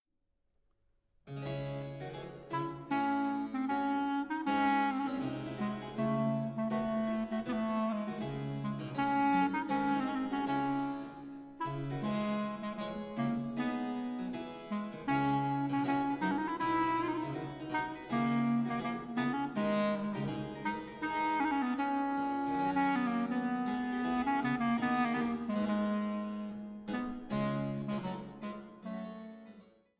Kantate für Sopran, Violine (Sopranblockflöte) und B. c.